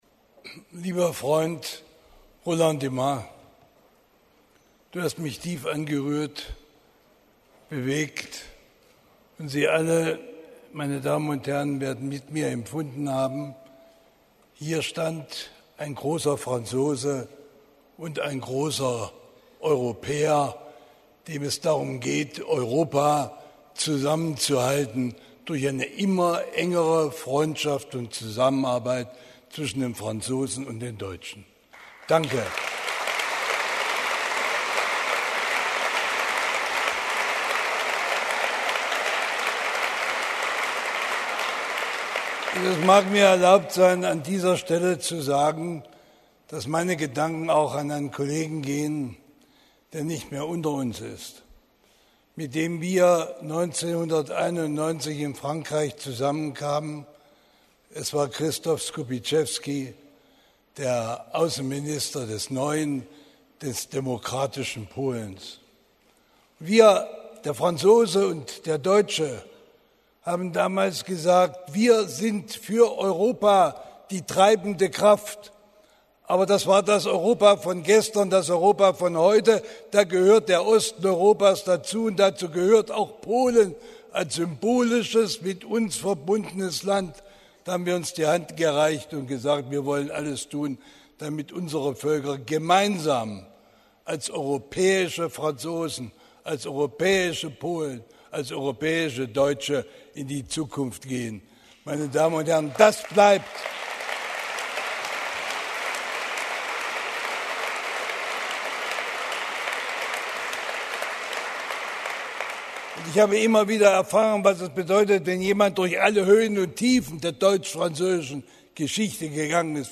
Wer: Hans-Dietrich Genscher, Bundesaußenminister a. D.
Was: Publishers‘ Night, Verleihung der Goldenen Victoria für das Lebenswerk
Wo: Berlin, Hauptstadtrepräsentanz, Deutsche Telekom AG